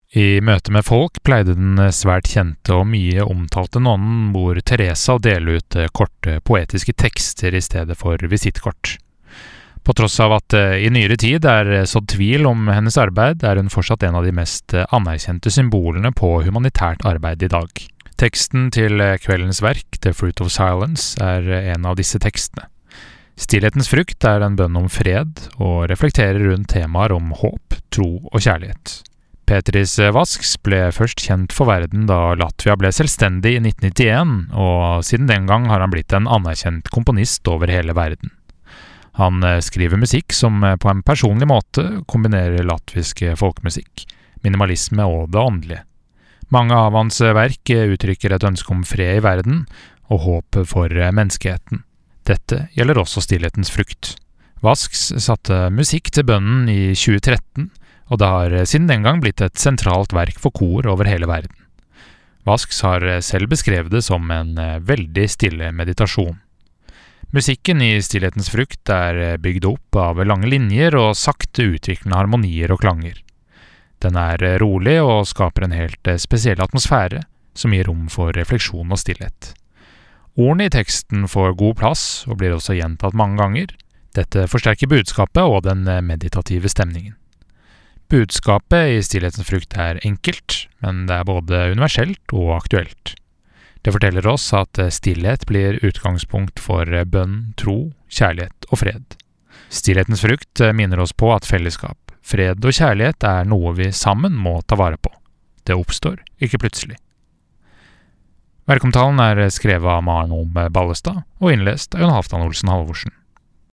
VERKOMTALE